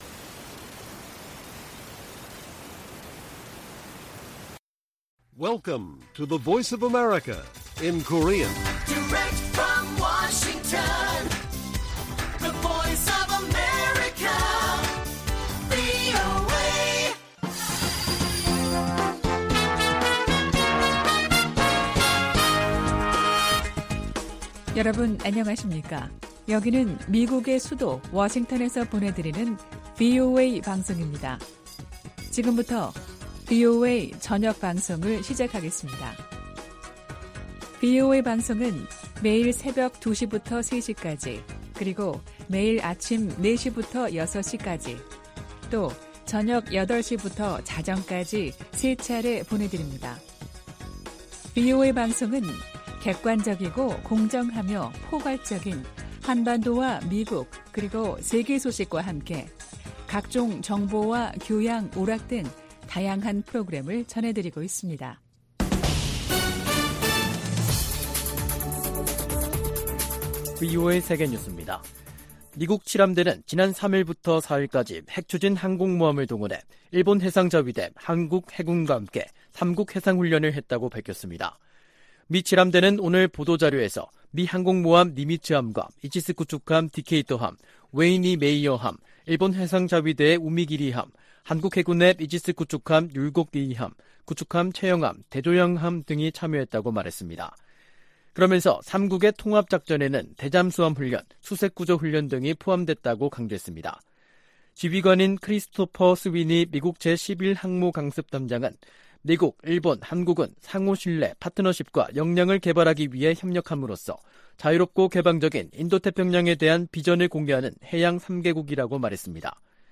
VOA 한국어 간판 뉴스 프로그램 '뉴스 투데이', 2023년 4월 4일 1부 방송입니다. 미 국방부는 북한 핵실험을 계속 감시하고 있으며, 궁극적인 목표는 여전히 한반도 비핵화라고 거듭 확인했습니다. 4월 중 북한에 여러가지 행사들이 이어진 가운데, 미한 정상회담 등을 계기로 대형 도발 가능성도 제기되고 있습니다. 백악관 국가안보보좌관과 신임 한국 대통령실 국가안보실장이 첫 전화 통화를 갖고 미한동맹 강화를 위한 협력을 다짐했습니다.